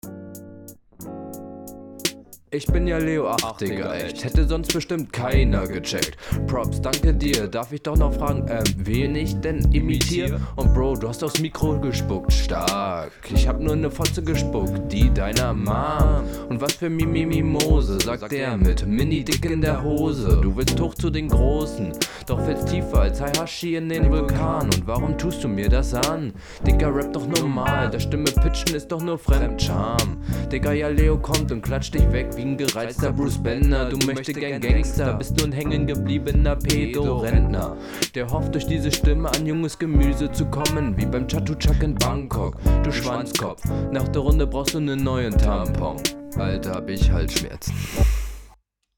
Doubles RR2 und HR1 halt leider bisschen unsauber.